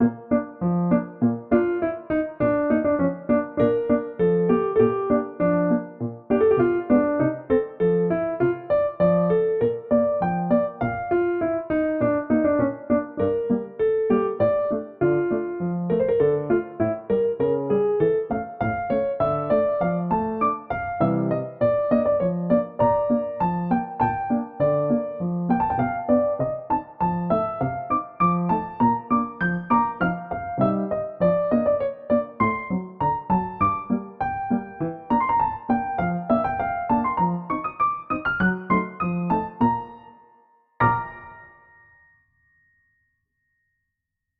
Piano accompaniment
Musical Period Romantic
Tempo 100
Rhythm Polka and 2/4 and Hornpipe
Meter 2/4